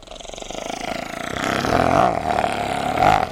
c_hyena_bat3.wav